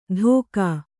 ♪ dhōka